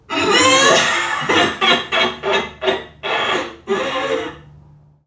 NPC_Creatures_Vocalisations_Robothead [47].wav